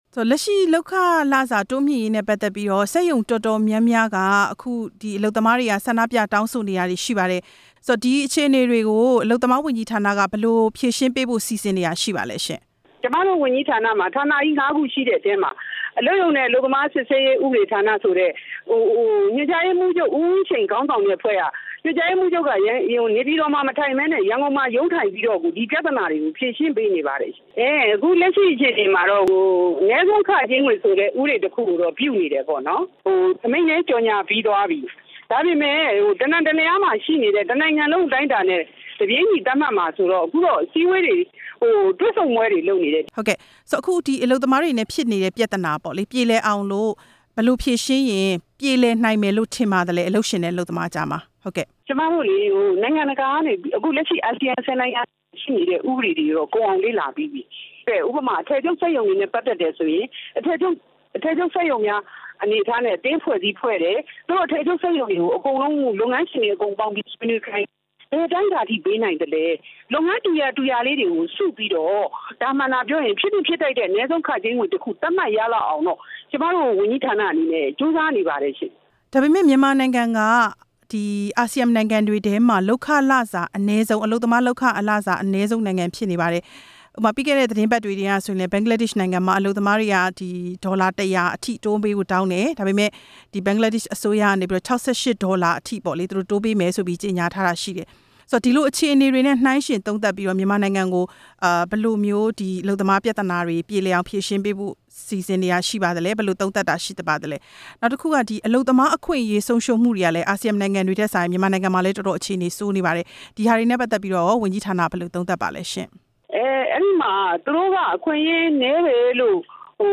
အလုပ်သမား ဒုတိယဝန်ကြီး ဒေါ်ဝင်းမော်ထွန်းနဲ့ မေးမြန်းချက်